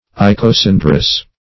Search Result for " icosandrous" : The Collaborative International Dictionary of English v.0.48: Icosandrian \I`co*san"dri*an\, Icosandrous \I`co*san"drous\, a. (Bot.)